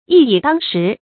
一以當十 注音： ㄧ ㄧˇ ㄉㄤ ㄕㄧˊ 讀音讀法： 意思解釋： 當：相當。